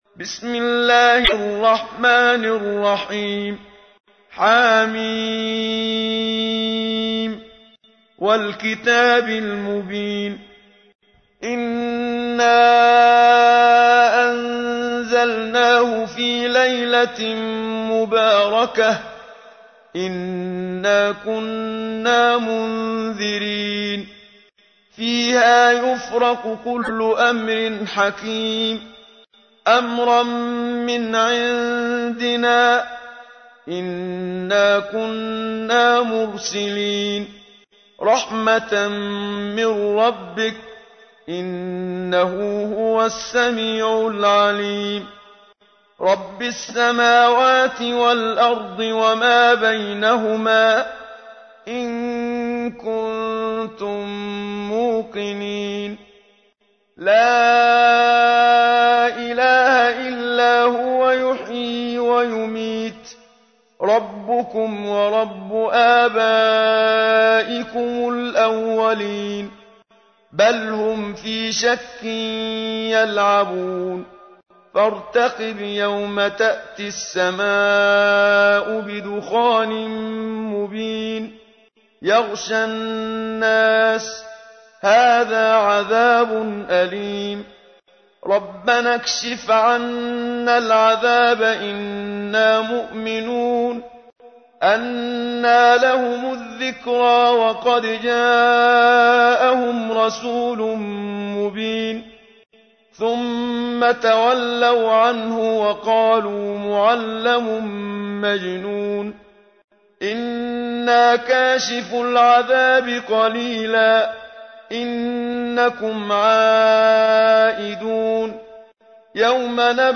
تحميل : 44. سورة الدخان / القارئ محمد صديق المنشاوي / القرآن الكريم / موقع يا حسين